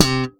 ALEM POPS C3.wav